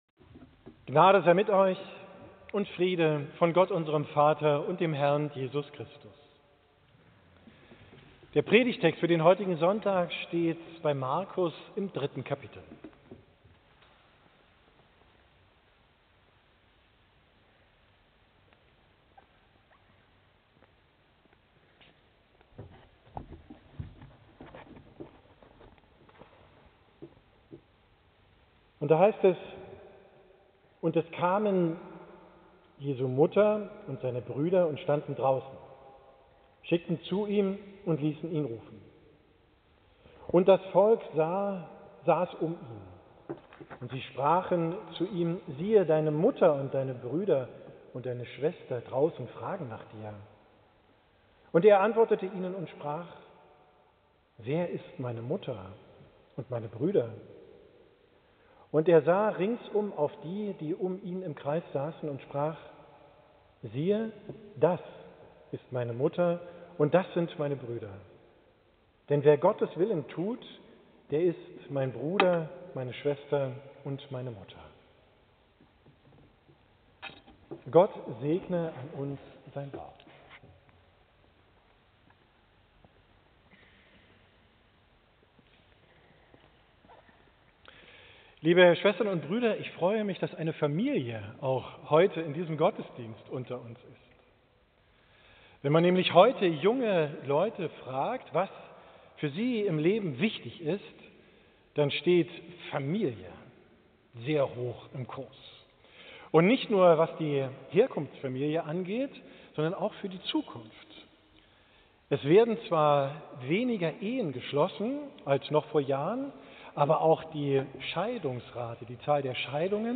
Predigt vom 13.